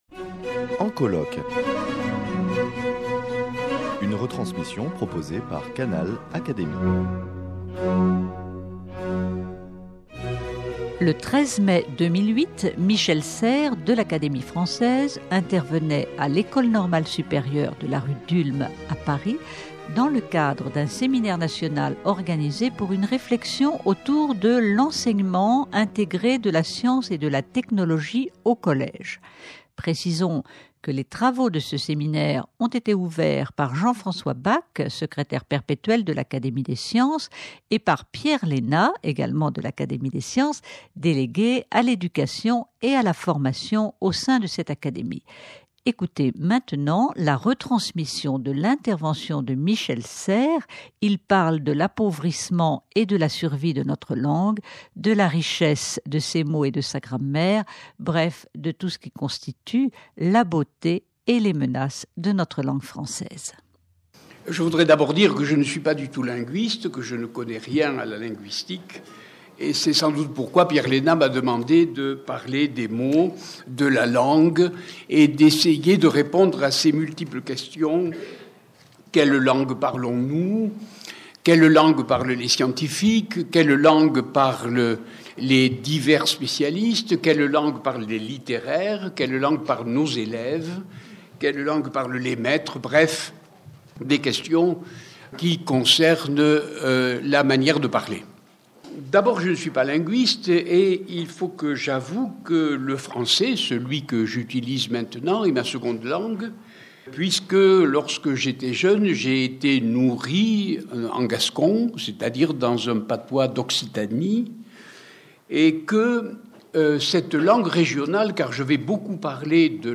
Michel Serres, de l’Académie française, intervenait à l’École Normale Supérieure, de la rue d’Ulm à Paris, le 13 mai 2008, dans le cadre d’un séminaire national portant sur l’enseignement intégré de la science et de la technologie au collège. Écoutez-le parler de l’appauvrissement et de la survie de notre langue, de la richesse de ses mots et de sa grammaire, bref, de tout ce qui constitue la beauté de notre langue et les menaces pesant sur elle.